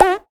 “咘咘咘泡泡”魔性音效>>>
BombExplosion011.ogg